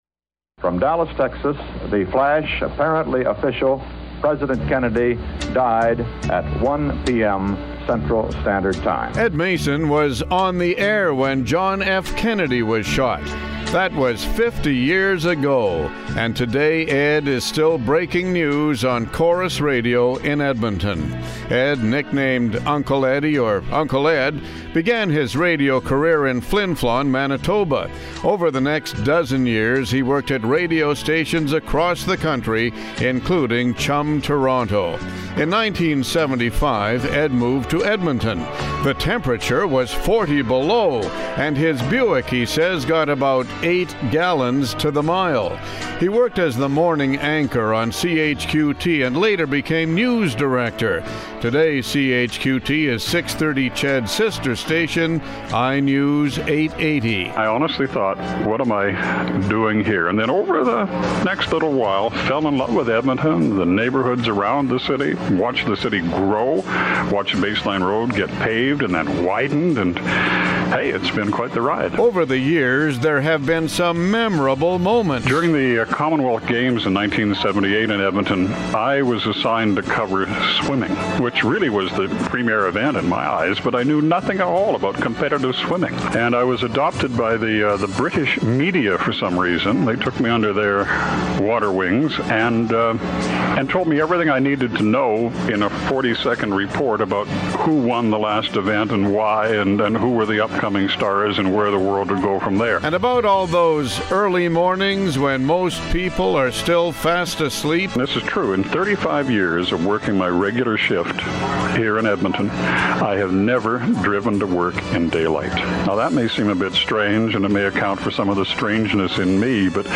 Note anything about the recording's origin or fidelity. Good morning everyone, at 8:45 this morning, we paid tribute to one of Canada’s great radio newsmen.